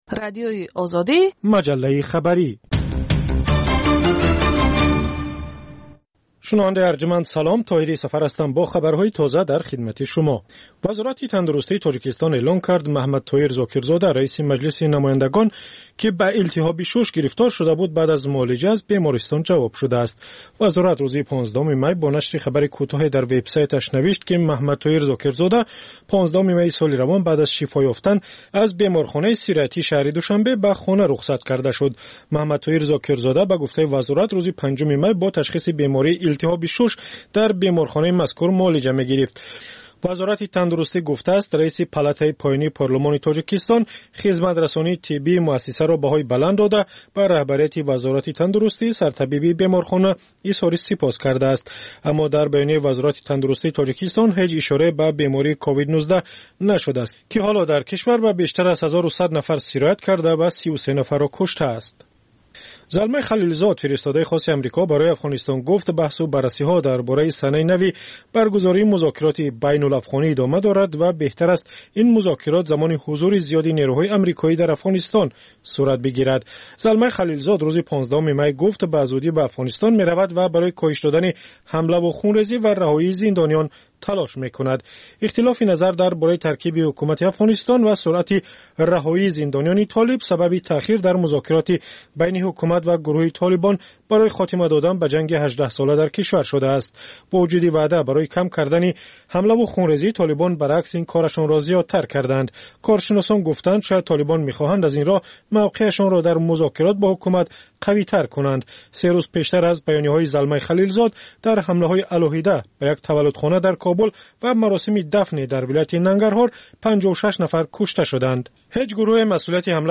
Маҷаллаи шомгоҳӣ